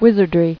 [wiz·ard·ry]